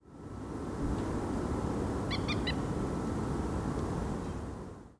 By far the commonest is a burst of harsh kek notes, often in groups of three or four. These can be given as a single burst, as in the top example below, or repeated every few seconds.
Notes in the middle of a sequence are usually higher than those at the beginning and end.
Moorhen single kek-kek-kek